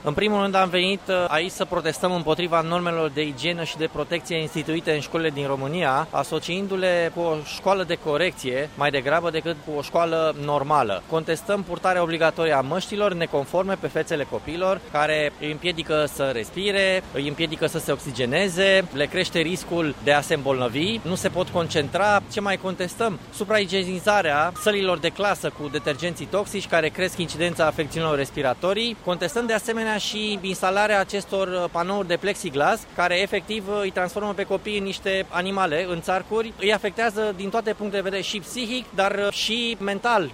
Câteva sute persoane au ieşit ieri în stradă la Bucureşti la un protest faţă de purtarea măştilor. Oamenii s-au adunat în Piaţa Universităţii şi au contestat măsurile de distanţare şi protecţie sanitară.
protestatar.mp3